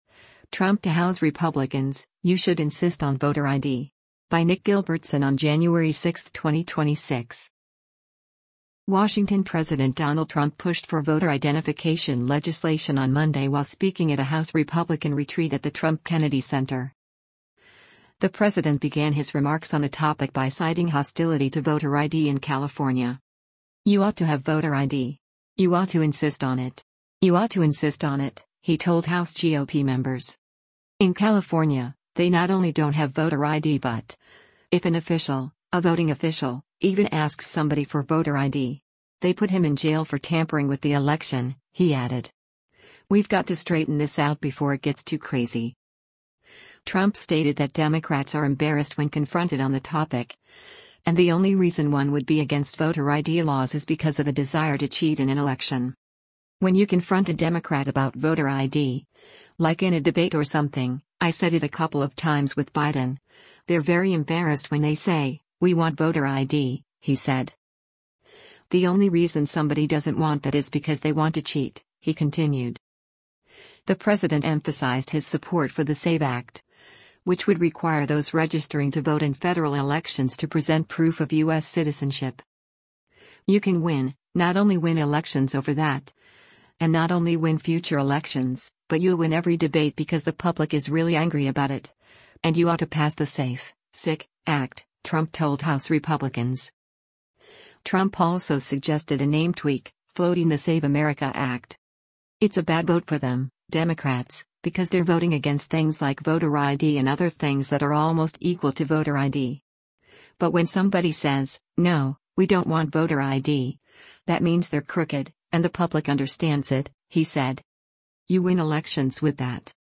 President Donald Trump addresses a House Republican retreat at The John F. Kennedy Center
WASHINGTON–President Donald Trump pushed for voter identification legislation on Monday while speaking at a House Republican retreat at the Trump-Kennedy Center.